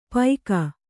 ♪ paika